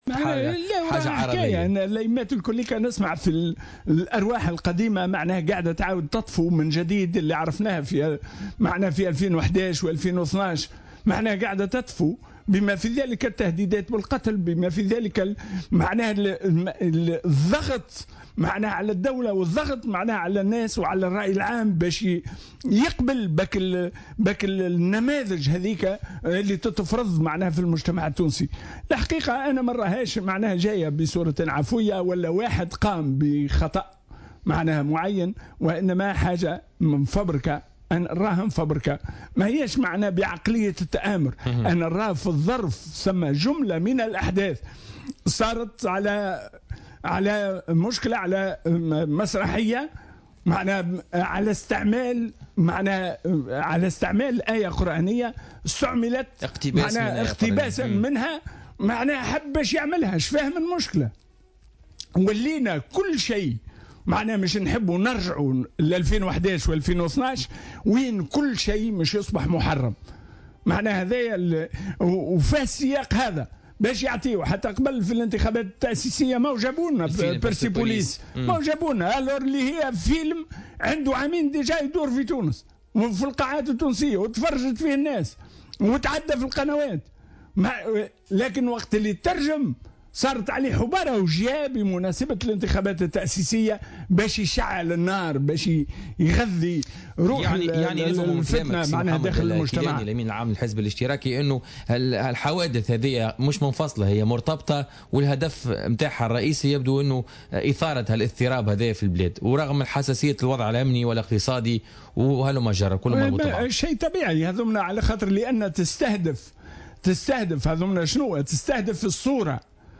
وأضاف الكيلاني، ضيف برنامج "بوليتيكا" اليوم أن العملية معدّة مسبقا وهي على علاقة بأحداث أخرى على غرار الاحتجاجات الأخيرة التي عرفتها جهة الجم للمطالبة بغلق نقطة لبيع الخمر والجدل الذي رافق اقتباس عنوان عمل مسرحي من آية قرآنية ، مشيرا إلى أن الهدف من ذلك هو الضغط على الدولة والرأي العام، حسب قوله.